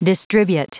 Prononciation du mot distribute en anglais (fichier audio)
Prononciation du mot : distribute